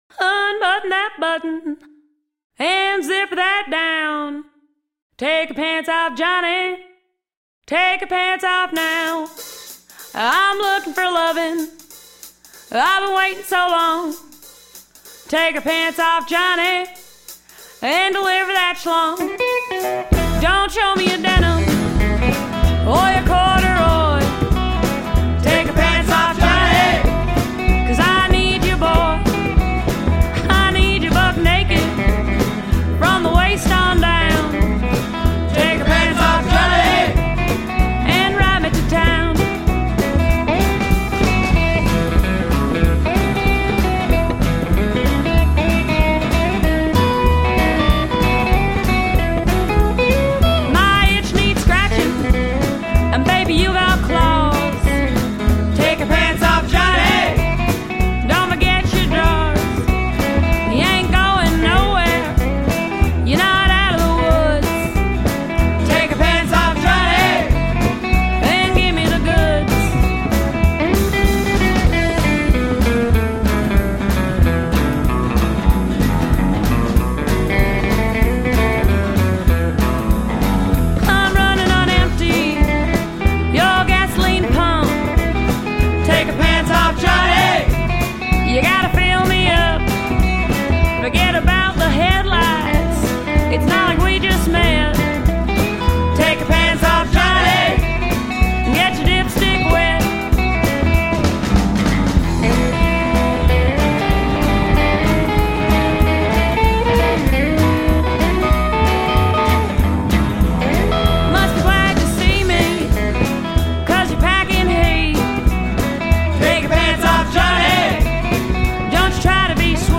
Country, rockabilly, western swing and lounge.
Tagged as: Alt Rock, Folk-Rock, Country